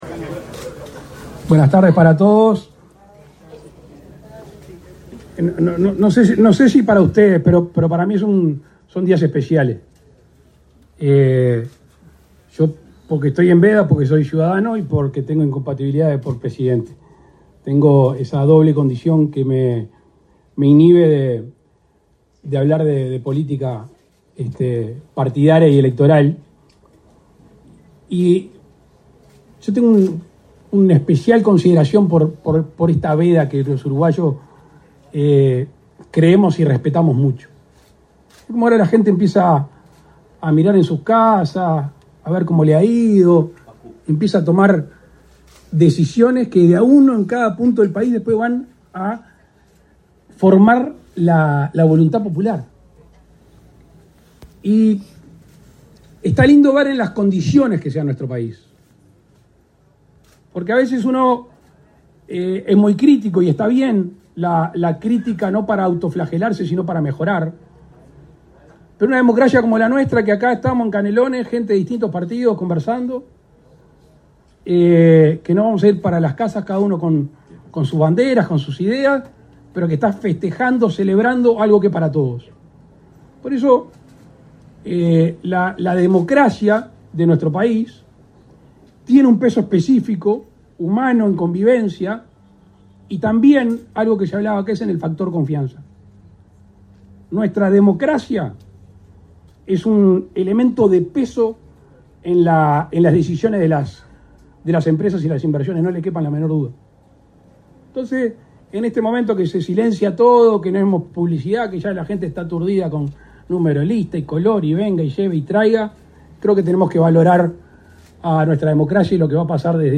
Palabras del presidente de la República, Luis Lacalle Pou
Con la participación del presidente de la República, Luis Lacalle Pou, este 25 de octubre, el Ministerio de Transporte y Obras Públicas inauguró obras